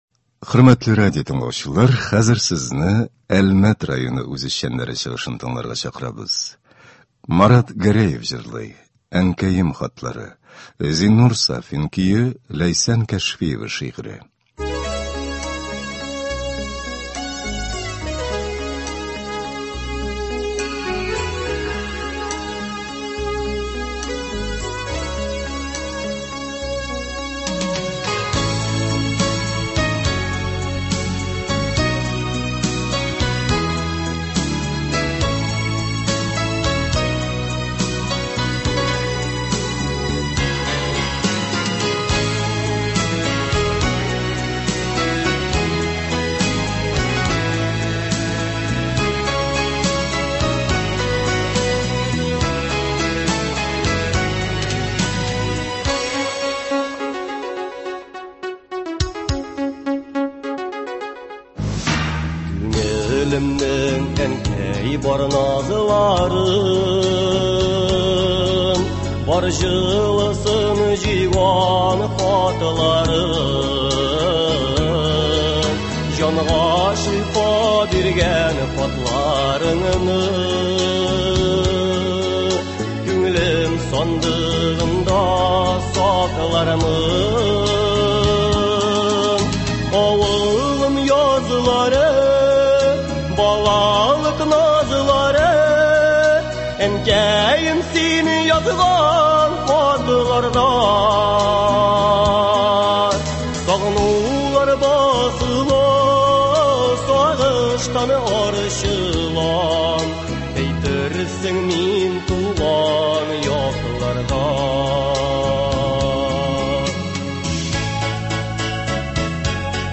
Концерт (23.10.23)